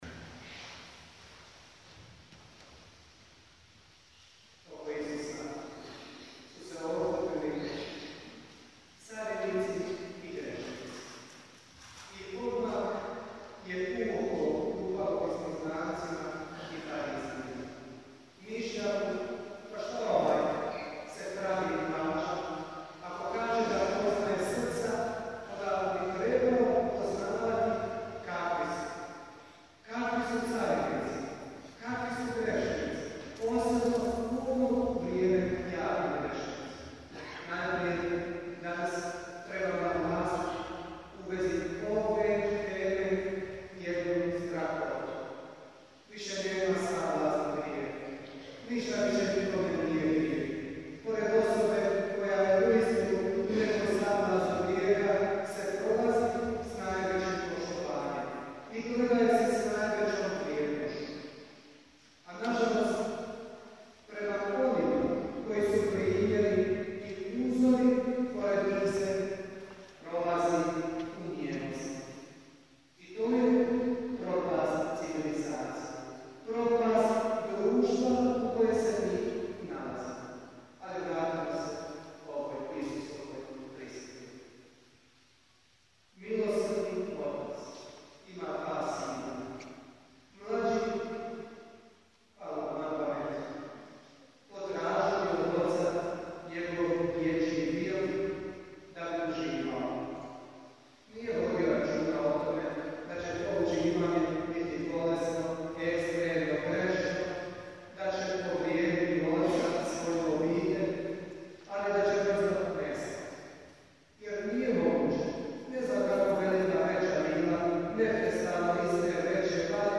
PROPOVIJED